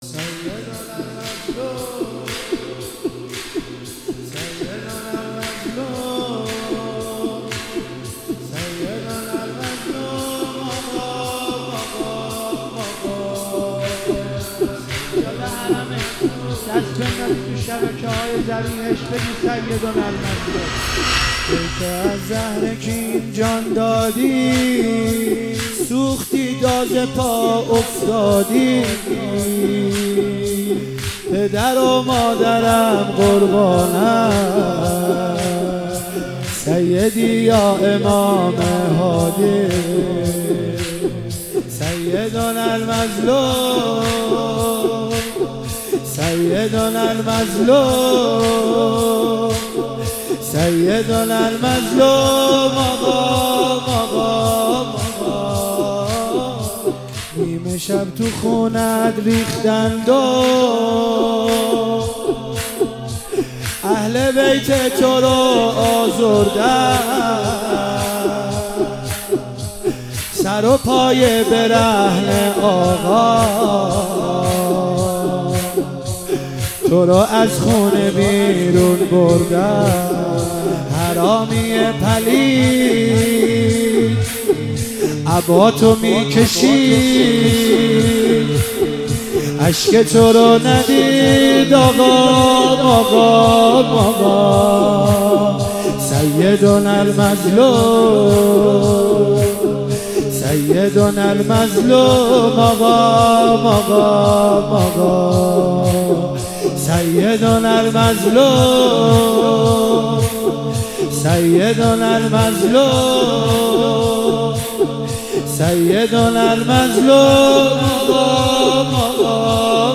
مداحی زمینه امام هادی علیه السلام
شهادت امام هادی علیه السلام 1400